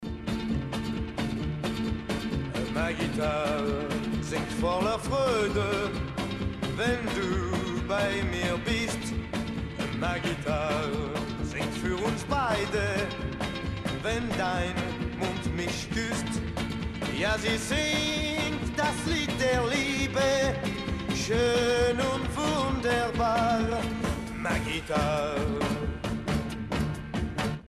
Rock'n'Roll
Hitsingle